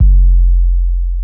TM 808.wav